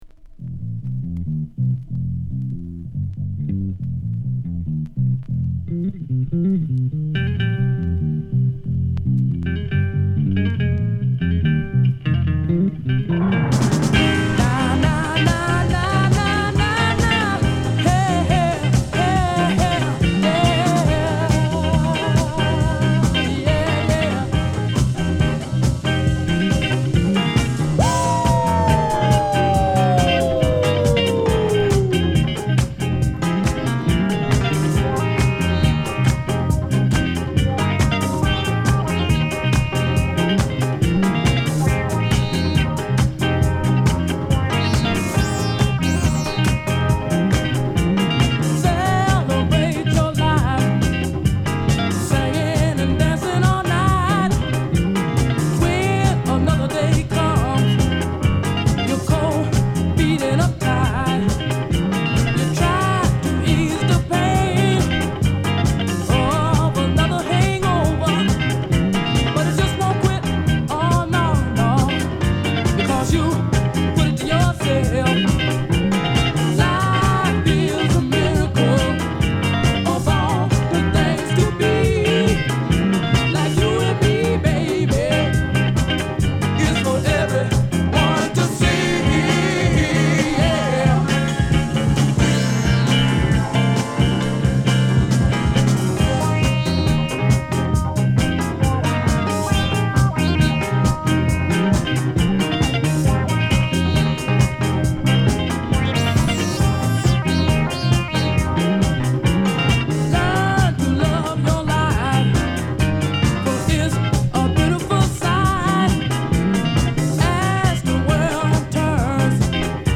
(FullVersion)
(45 O.G Version Loud Cut)